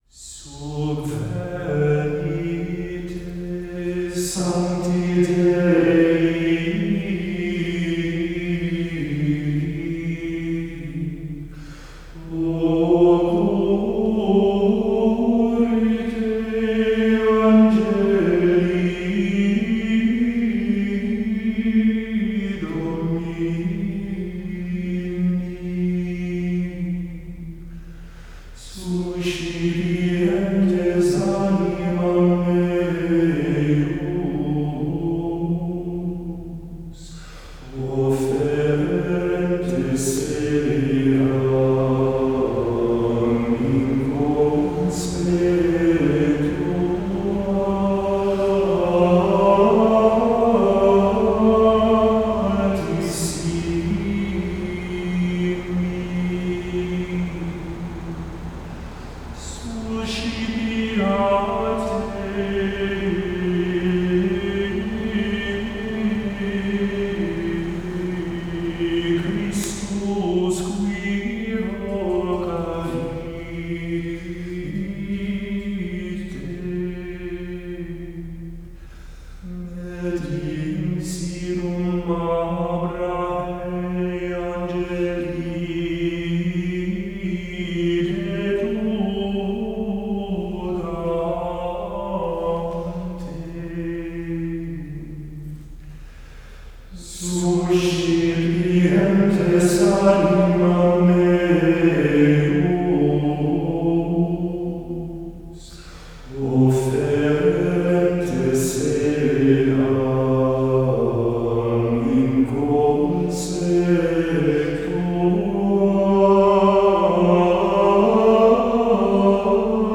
Gregorian Chant: A Sacred Tradition
by Church Music Association of America | Parish Book of Chant, 2nd edition